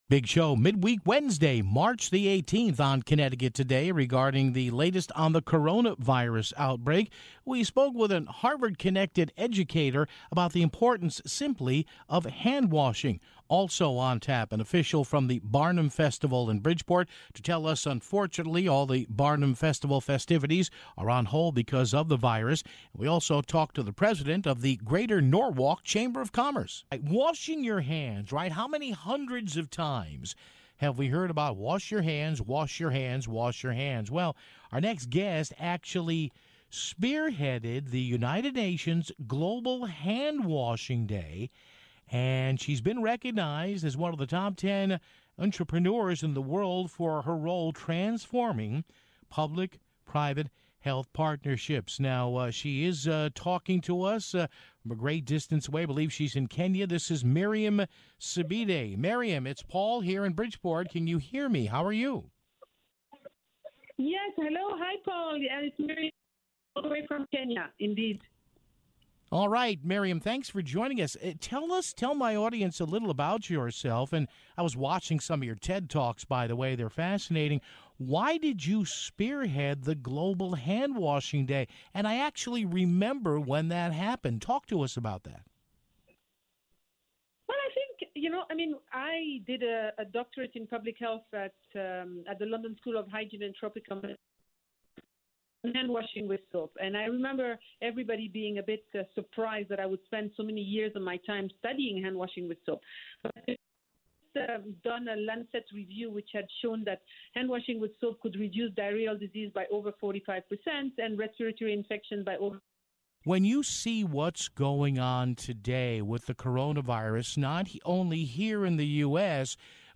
Then the New Haven Chamber of Commerce called to give us an update.